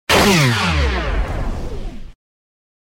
Power Failure Sound Effect
Power outage sound effect: generator shutdown, large device powering off, and electricity loss.
Power-failure-sound-effect.mp3